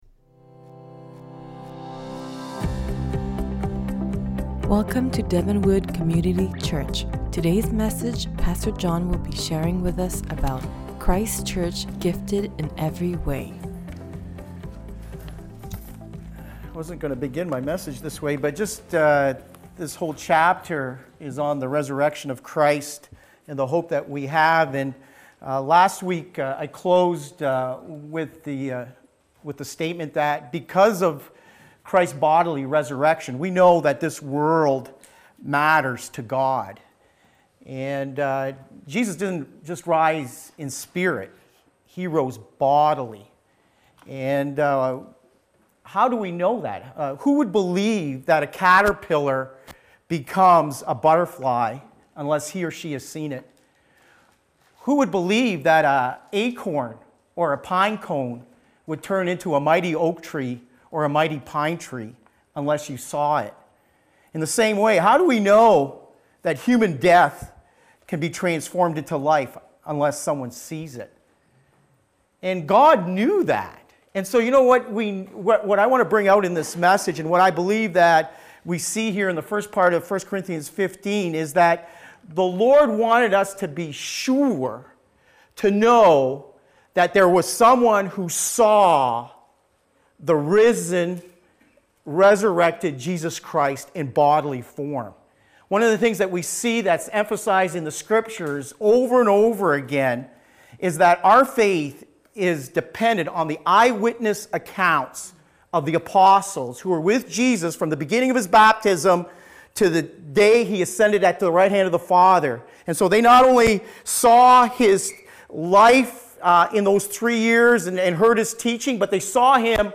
Sept-3-2017-Sermon_mixage-final.mp3